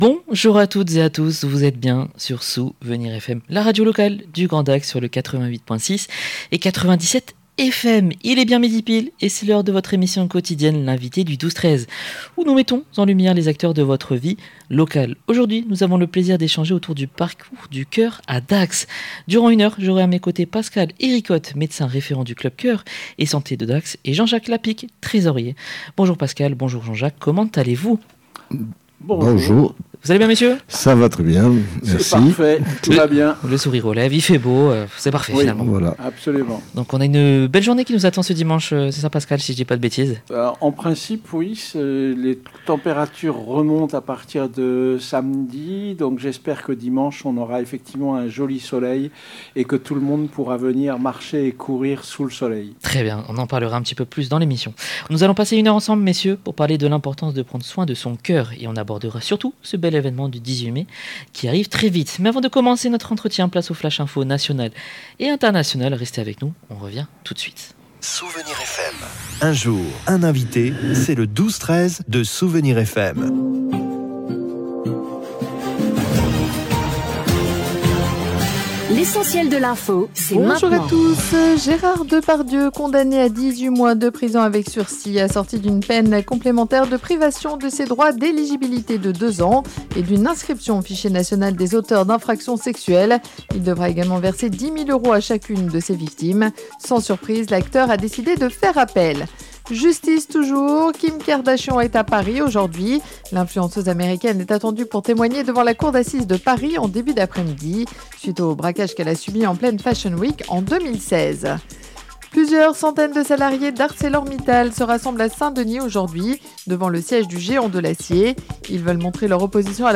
L’émission